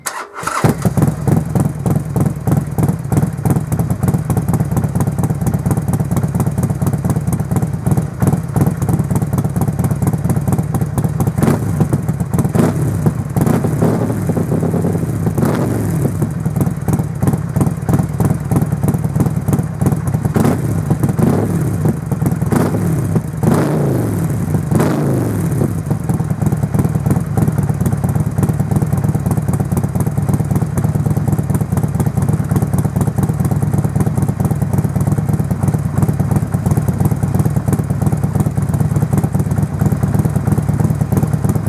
Catégorie 3 : Bruit raisonnable de + à ++
Vous les entendrez suffisamment en roulant et les passants se retourneront pour voir ce qui produit ces jolis tempos graves.
Akrapovič sur Sportster XL1200 -Sans catalyseur et sans chicane